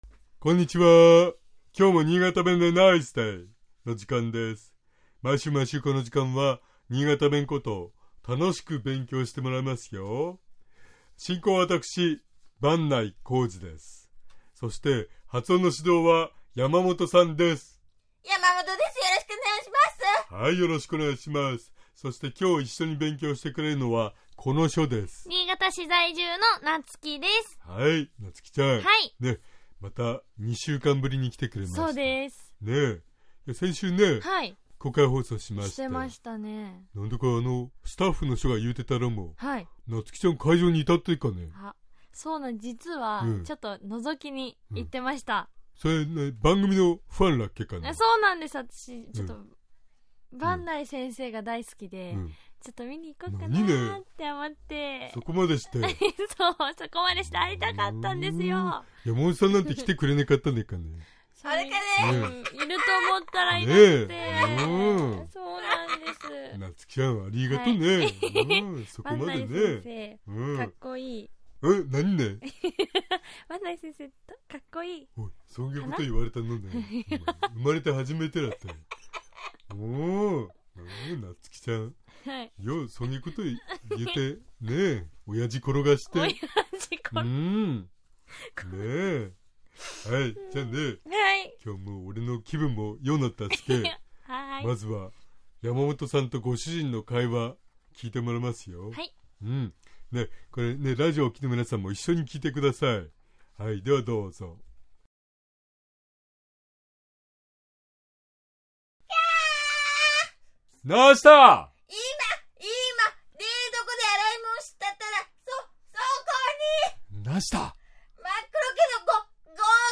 （スキット）
尚、このコーナーで紹介している言葉は、 主に新潟市とその周辺で使われている方言ですが、 それでも、世代や地域によって、 使い方、解釈、発音、アクセントなどに 微妙な違いがある事を御了承下さい。